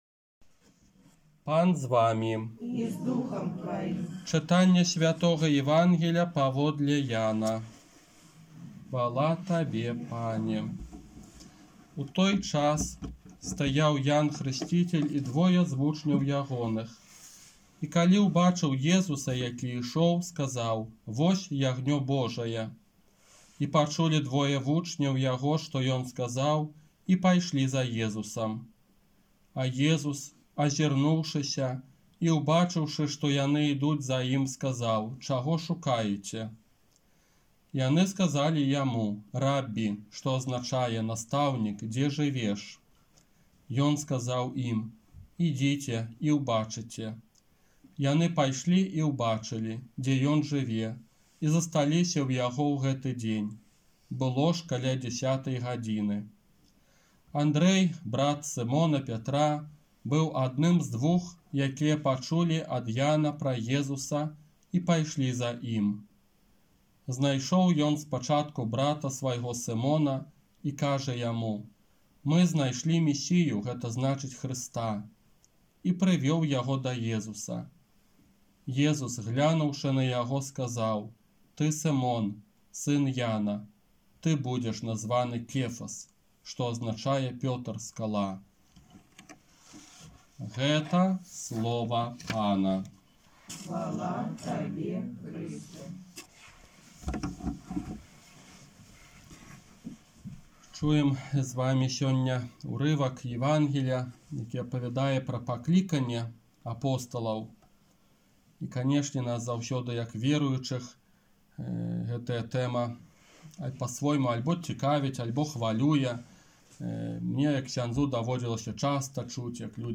ОРША - ПАРАФІЯ СВЯТОГА ЯЗЭПА
Казанне на другую звычайную нядзелю 17 студзеня 2021 года